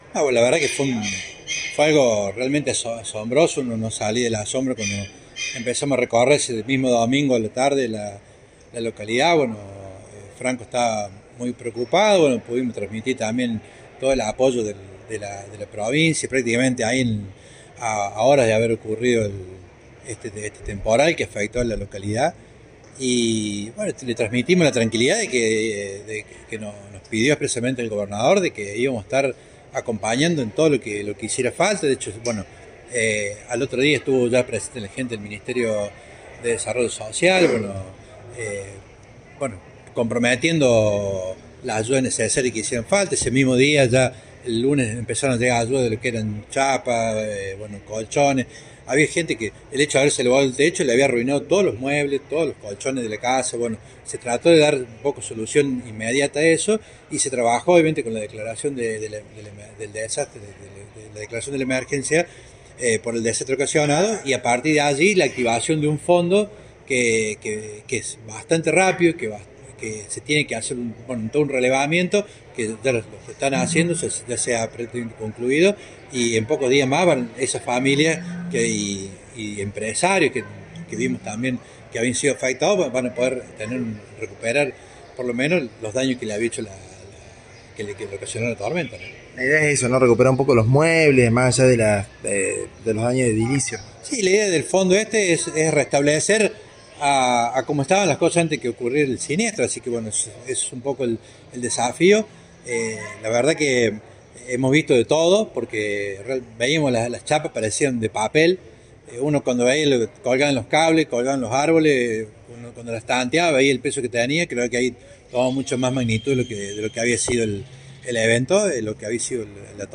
En diálogo con este medio dijo que se hicieron los relevamientos desde el primer día y que el objetivo del fondo es que todo se pueda reestablecer a cómo estaba antes del temporal.